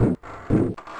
mech_walk.ogg